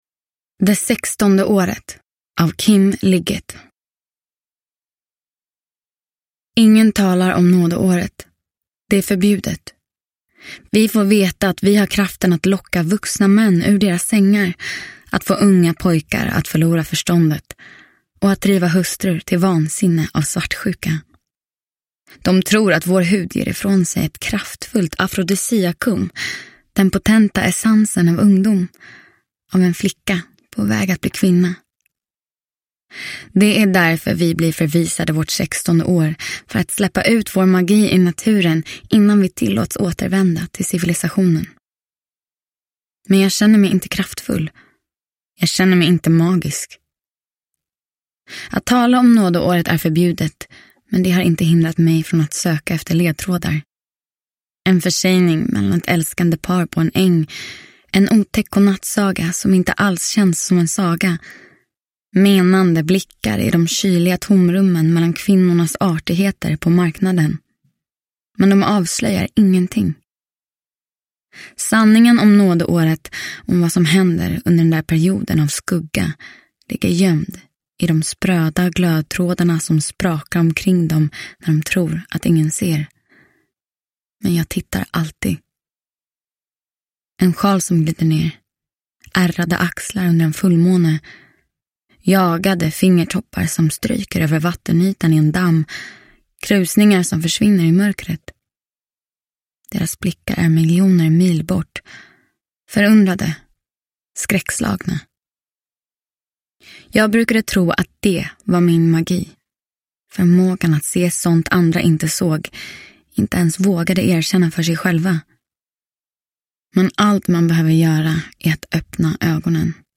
Uppläsare: Hedda Stiernstedt